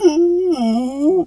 bear_pain_whimper_11.wav